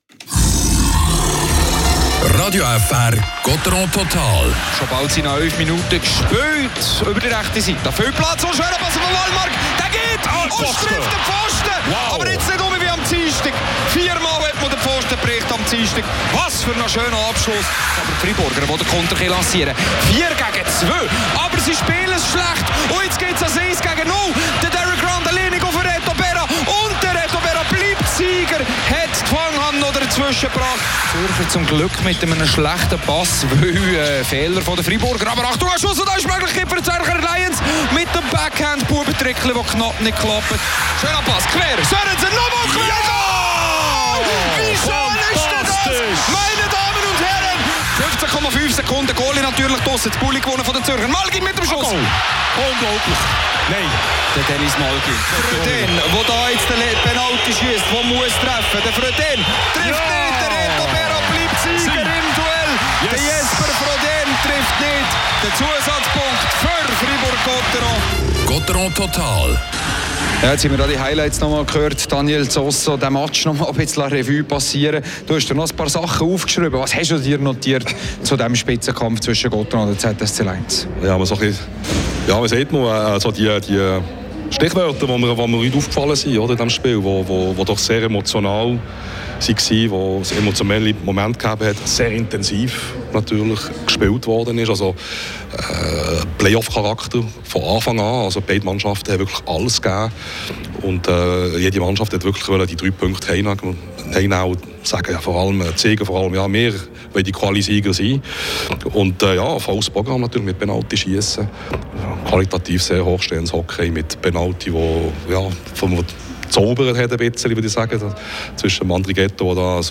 Analyse von Experte